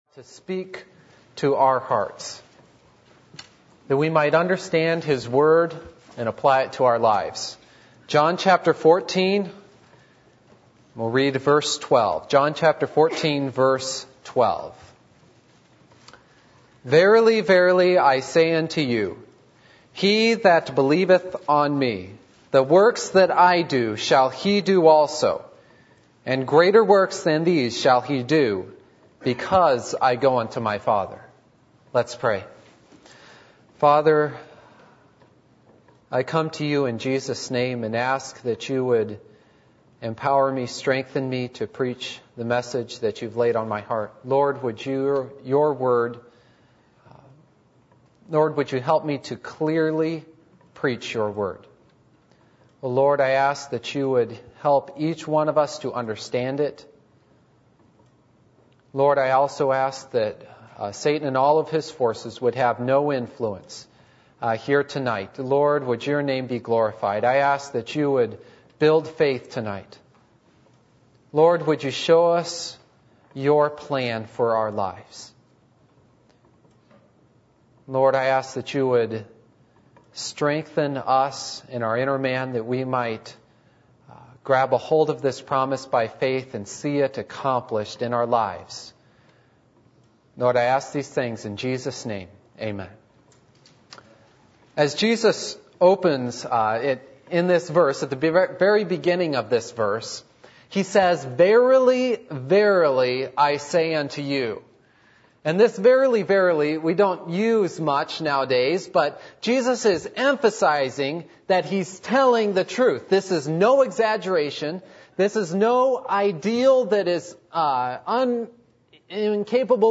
John 14:7-12 Service Type: Midweek Meeting %todo_render% « Blessing By Association Do You Have The Joy Of The Lord?